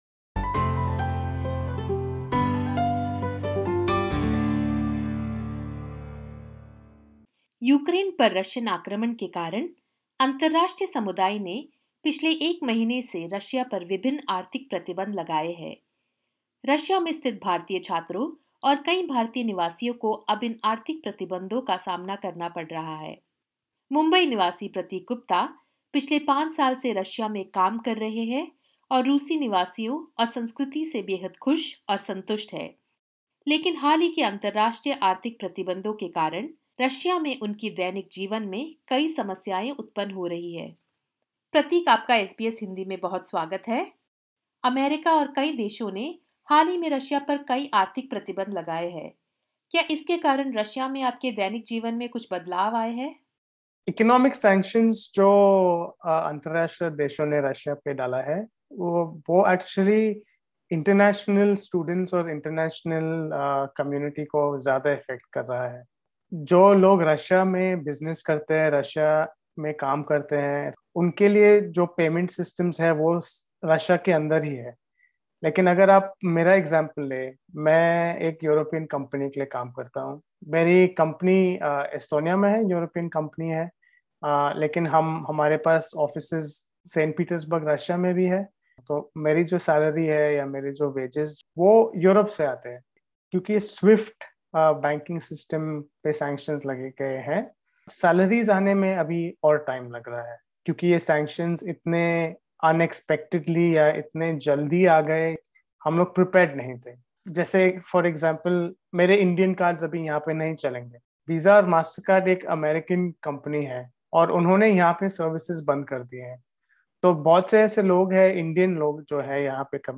Listen to this podcast in which SBS Hindi talks to some Indian citizens living in Russia who are facing difficulties due to these sanctions.